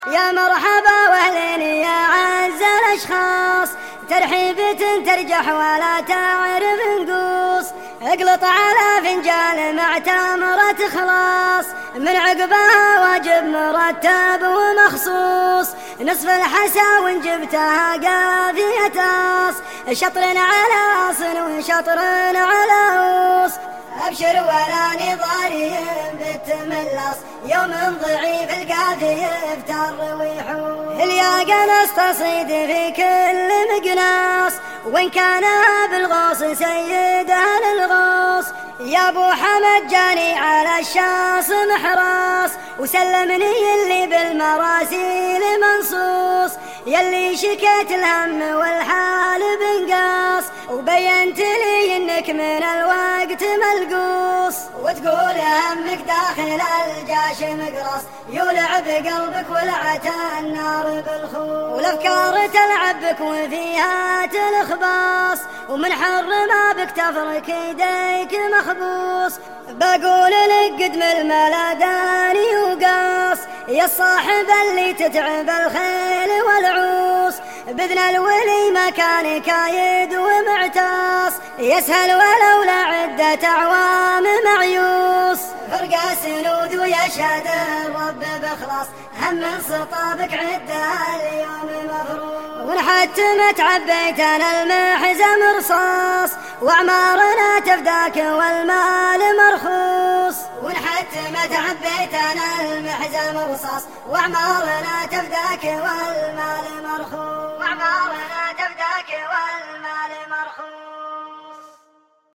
ردية